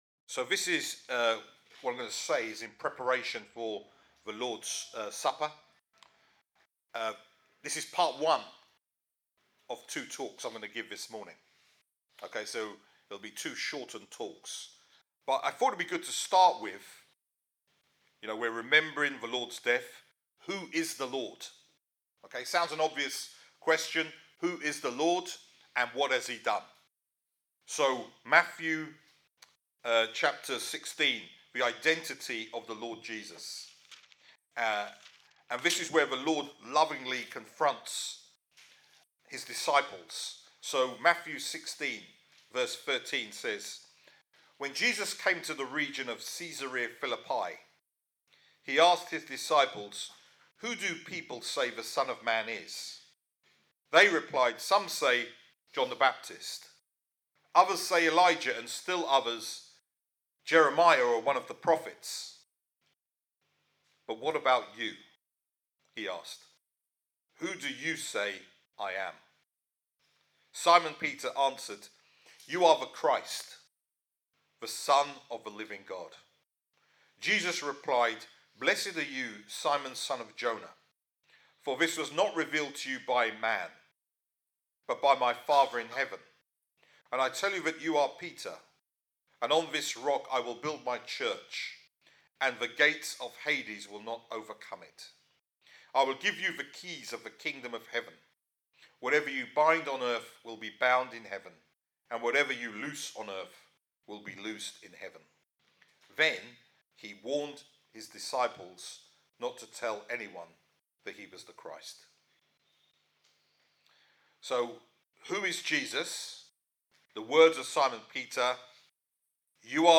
Sermon Part 1